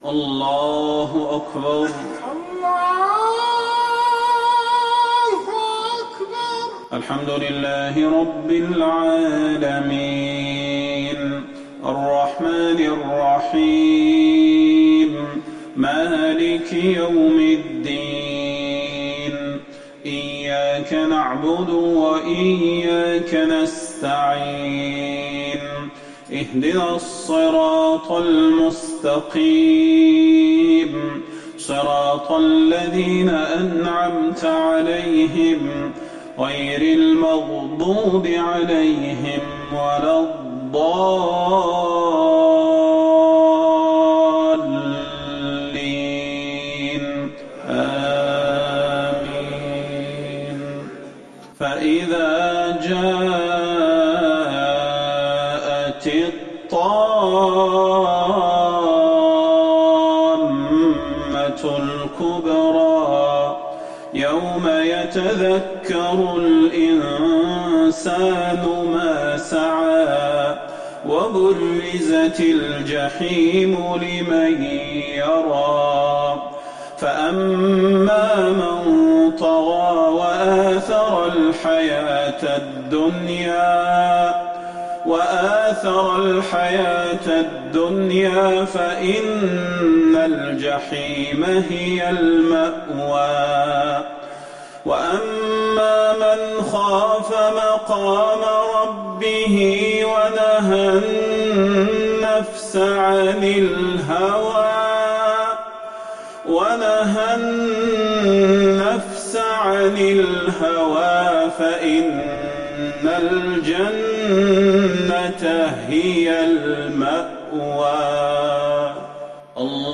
صلاة العشاء للقارئ صلاح البدير 9 جمادي الآخر 1441 هـ